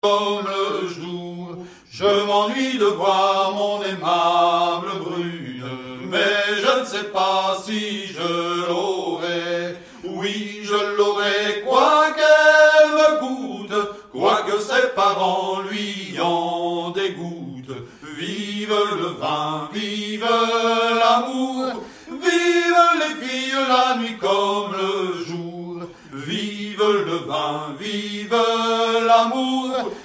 Musique : Traditionnel
Interprètes : Bonsoir Catin, Brou Hamon Quimbert
Origine : Louisiane
Chant par Bonsoir Catin sur l'album Vive L'Amour en 2009 (Youtube), par Brou Hamon Quimbert sur l'album À l'arrivée de mon retour en 2016 (